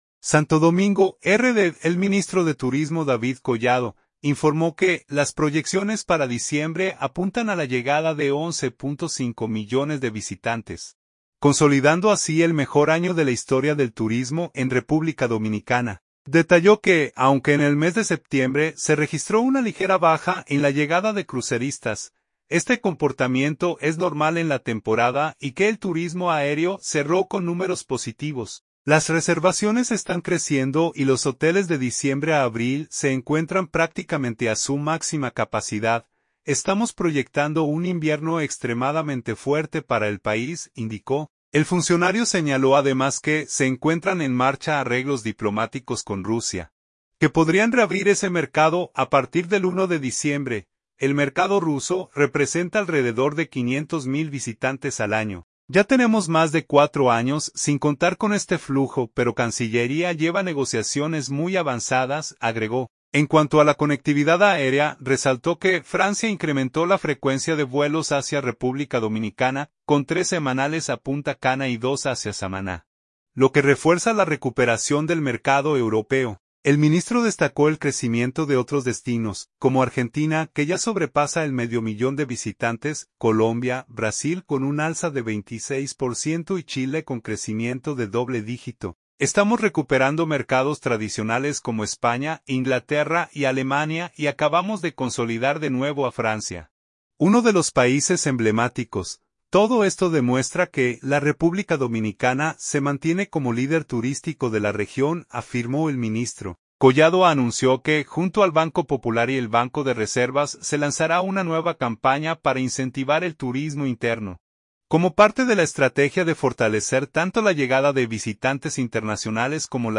David Collado habló del tema al dejar iniciados los trabajos de remozamiento del parque Anacaona, en Constanza.